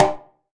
9HICONGASL.wav